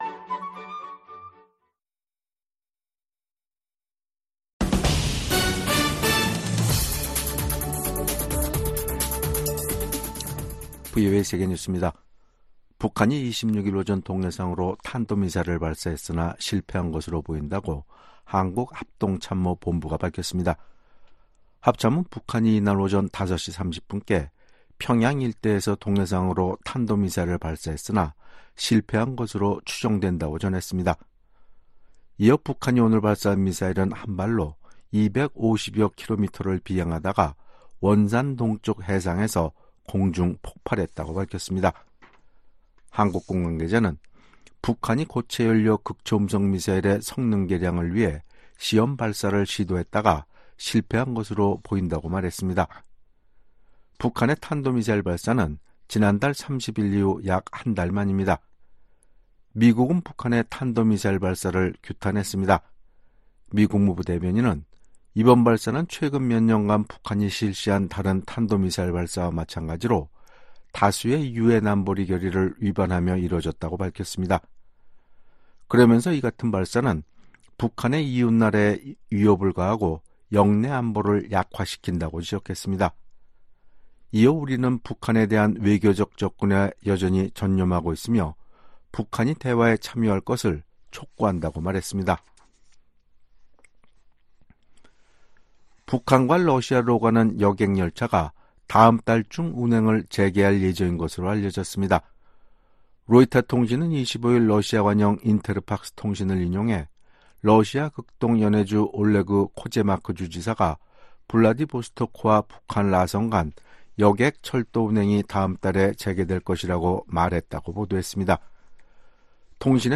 VOA 한국어 간판 뉴스 프로그램 '뉴스 투데이', 2024년 6월 26일 2부 방송입니다. 북한이 동해상으로 극초음속 미사일로 추정되는 발사체를 쏘고 이틀째 한국을 향해 오물 풍선을 살포했습니다. 미국 정부는 북한의 탄도미사일 발사가 다수의 유엔 안보리 결의 위반이라며 대화에 복귀할 것을 북한에 촉구했습니다. 미국 국방부는 북한이 우크라이나에 병력을 파견할 가능성에 대해 경계를 늦추지 않고 있다는 입장을 밝혔습니다.